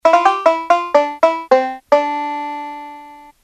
banjo.mp3